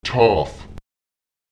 Lautsprecher táv [taùf] folglich, also